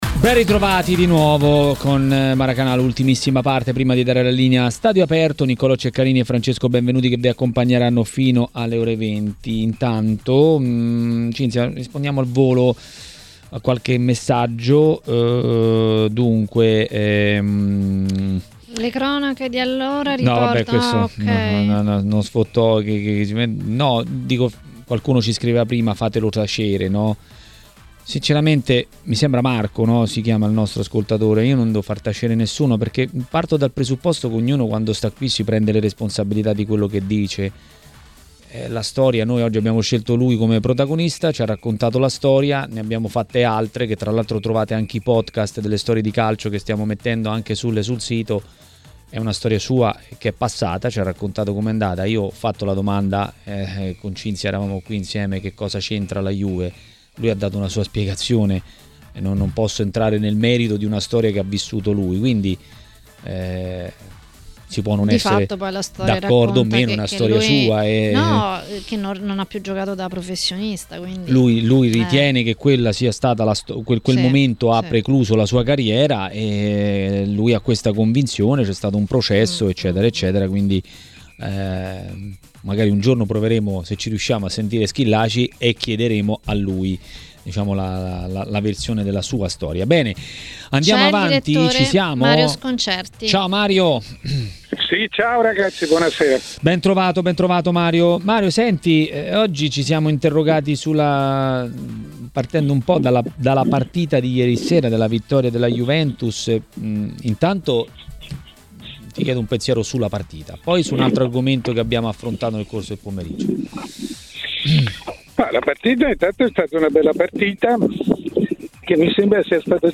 Il direttore Mario Sconcerti a Maracanà, nel pomeriggio di TMW Radio, ha parlato dei temi del giorno.